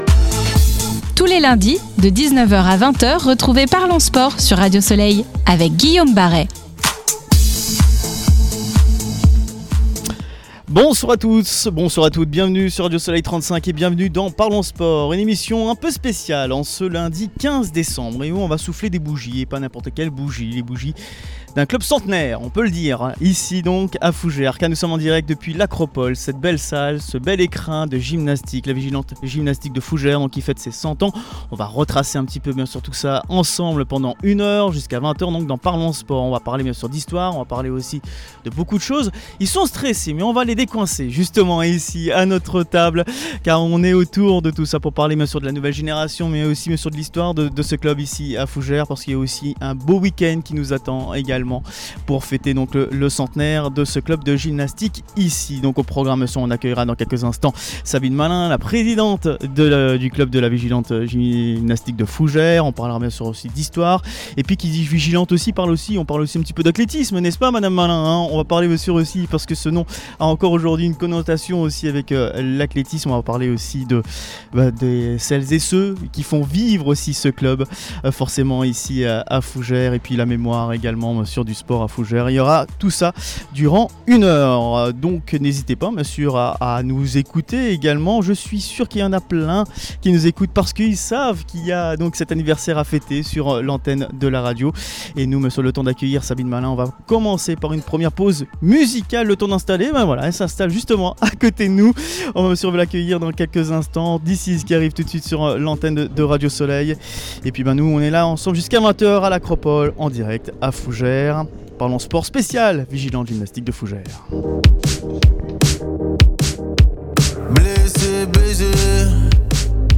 Ce lundi 15 décembre, en direct de l’Acropôle de Fougères, ParlonSport ! célèbre le centenaire de la Vigilante Gymnastique de Fougères qui a prévu un beau week-end pour fêter cela.